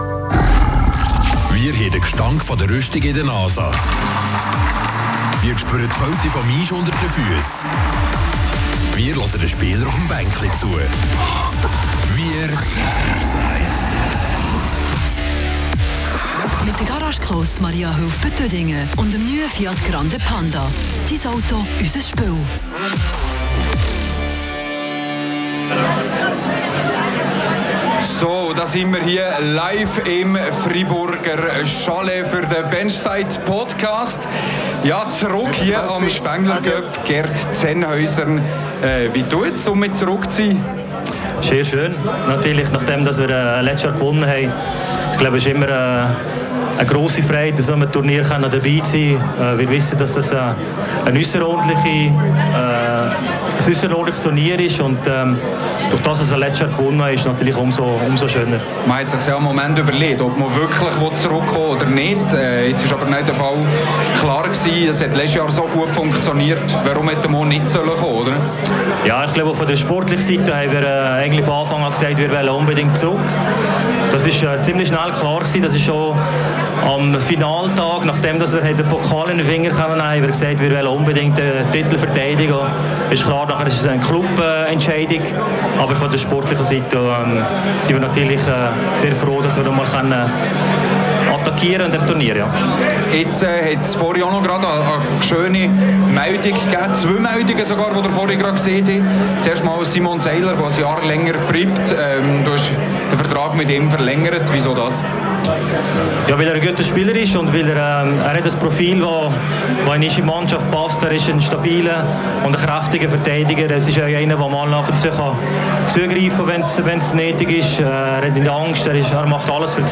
Beschreibung vor 4 Monaten Es geht wieder los, wir sind wieder in Davos am Spengler Cup. Die Mission Titelverteidigung ist lanciert!